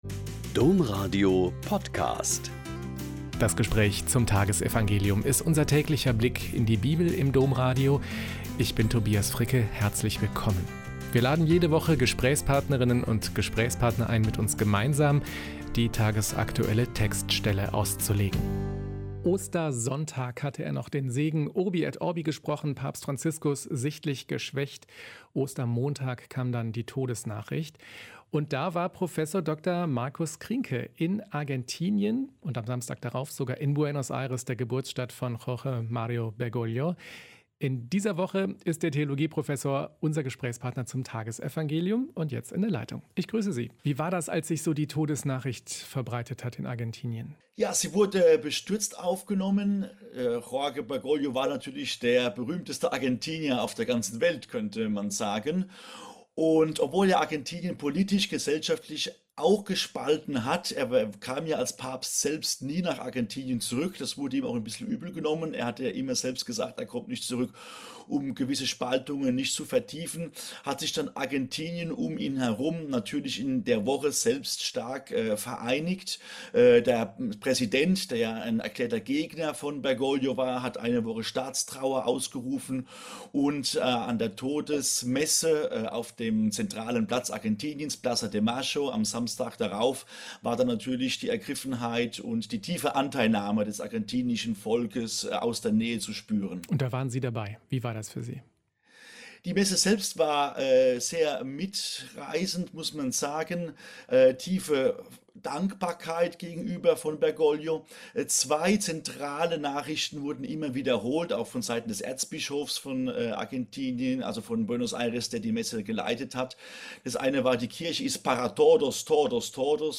Joh 14,27-31a - Gespräch